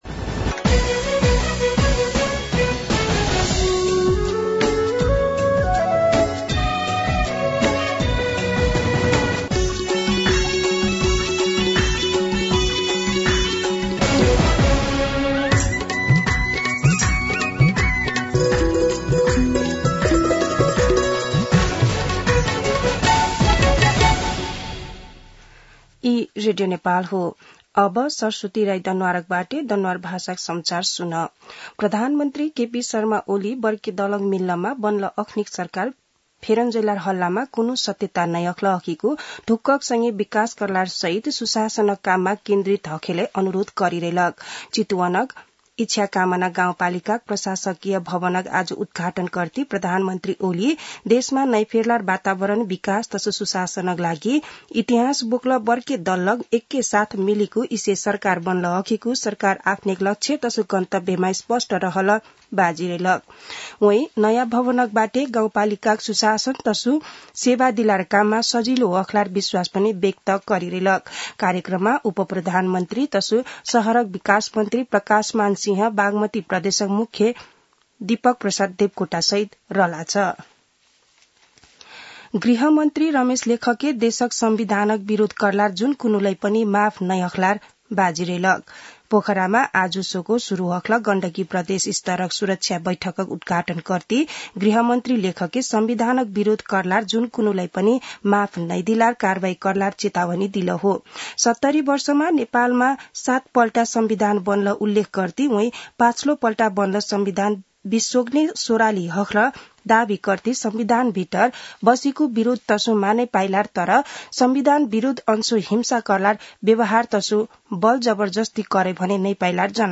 दनुवार भाषामा समाचार : ५ वैशाख , २०८२
Danuwar-News-8.mp3